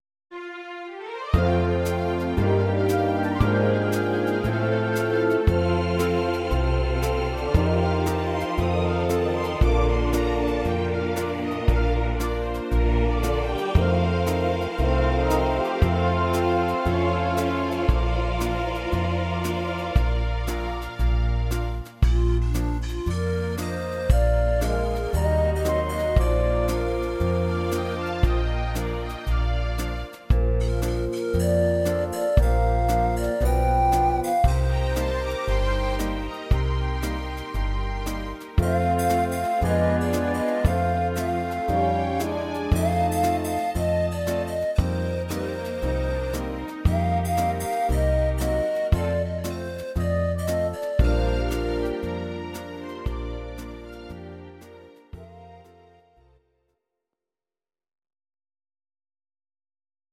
Audio Recordings based on Midi-files
Ital/French/Span, 1960s